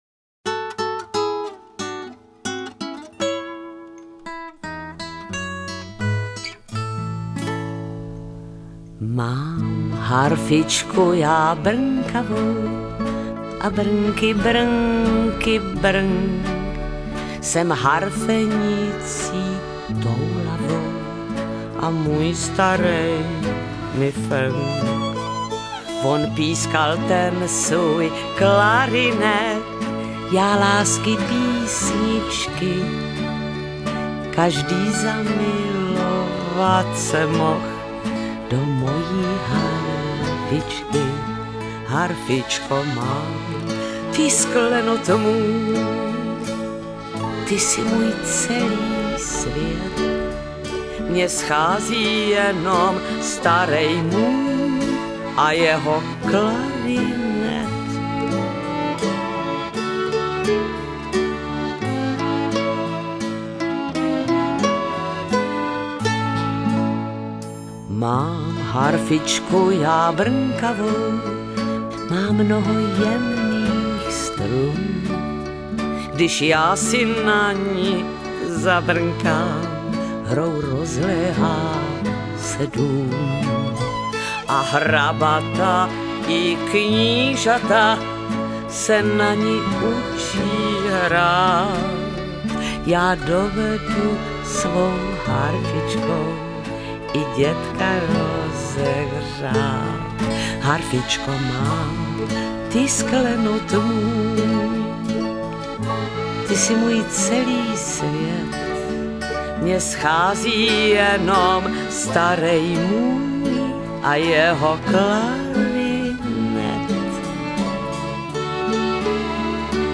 hrají a zpívají
Lidová / Lidová